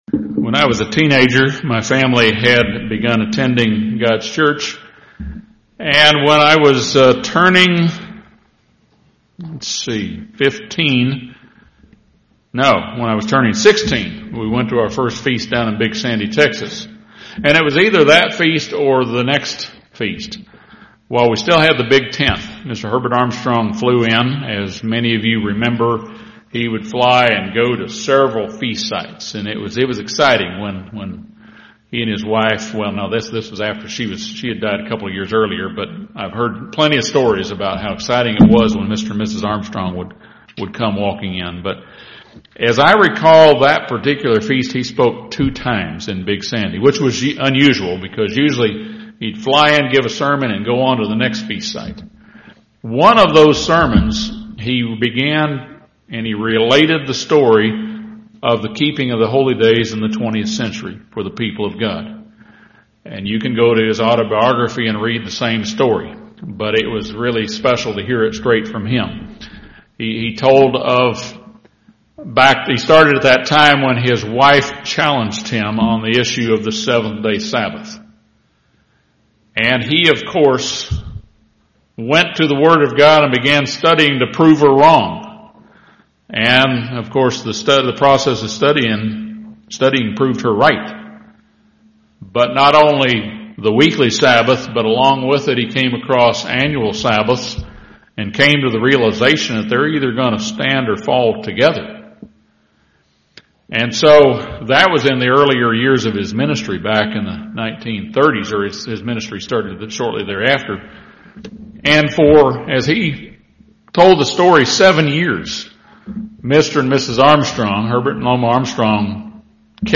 This sermon discusses steps to take in spiritually and physically preparing for the upcoming spring festival season.